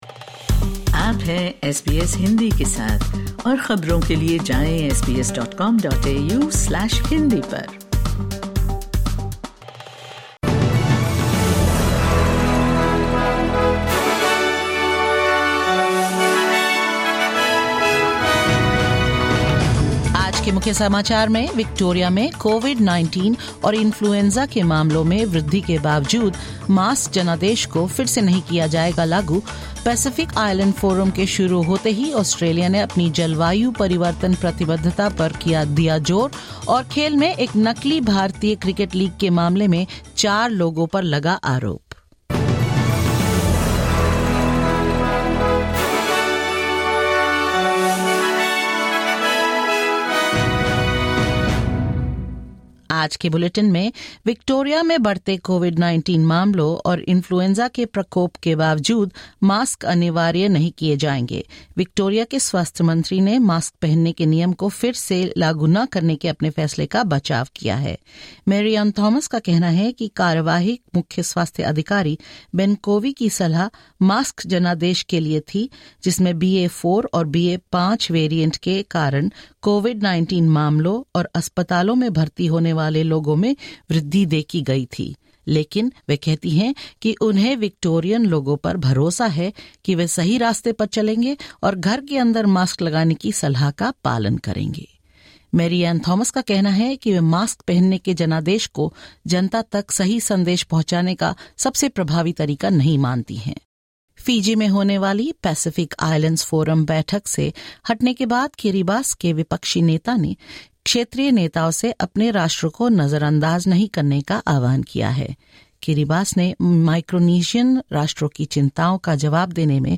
In this latest SBS Hindi bulletin: Victoria's Health Minister defends her decision to not re-introduce a mandate for wearing masks, as COVID-19 and flu cases surge; The Pacific Islands Forum underway with Australia keen to renew its commitment on climate change; Indian police arrests four people over a fake cricket tournament that took bets from unsuspecting Russians and more.